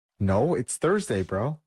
no its thursday bro Meme Sound Effect